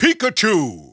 The announcer saying Pikachu's name in English and Japanese releases of Super Smash Bros. Brawl.
Pikachu_English_Announcer_SSBB.wav